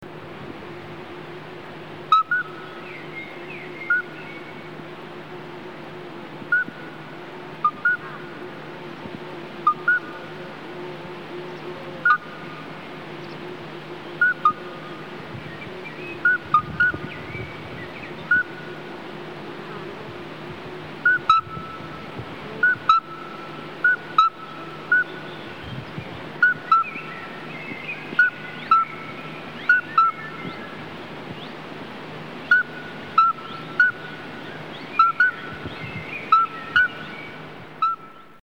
Geburtshelferkröte
Alytes obstetricans
Die Geburtshelferkröte ist vor allem in der Abenddämmerung und Nachts zu hören. Ihr "Läuten" ist unverwechselbar. Sie wird deswegen auch Glockenfrosch oder "Glögglifrosch" genannt.
geburtshelferkroete.mp3